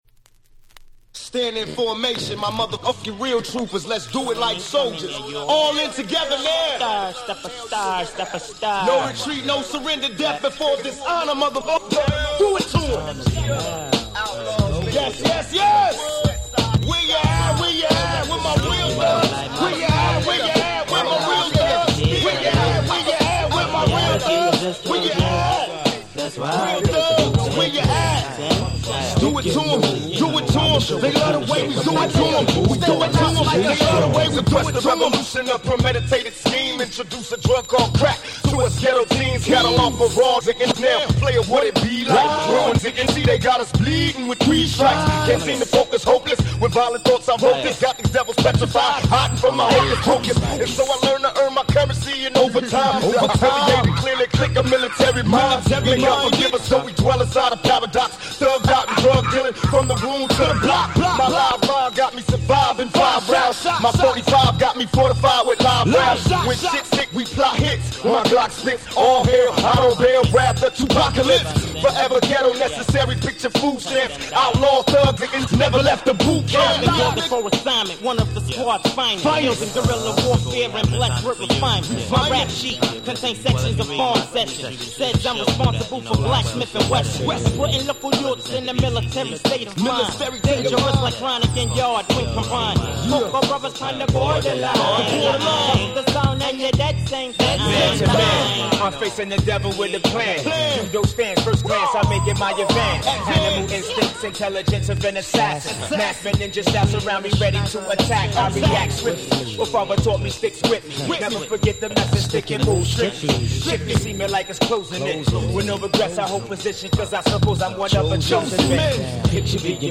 95' Dope Hip Hop !!